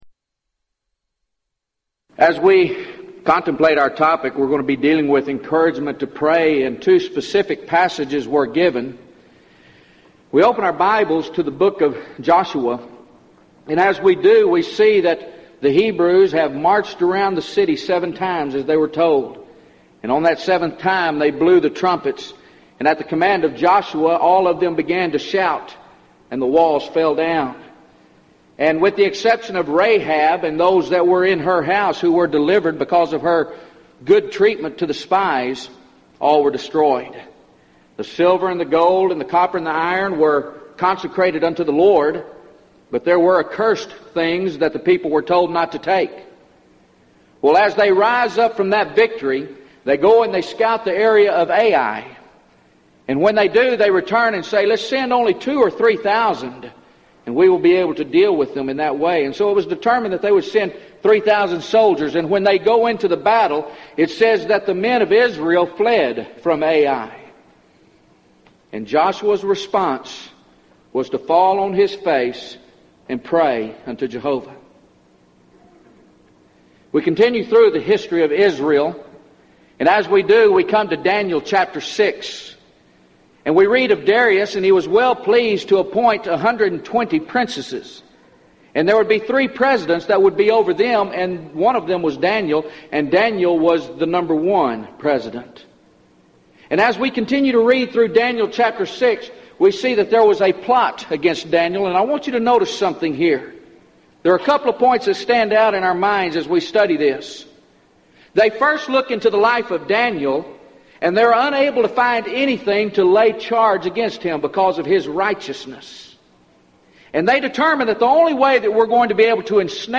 Event: 1998 Gulf Coast Lectures
lecture